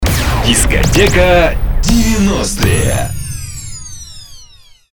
Категория: Скачать Фразы и Произношения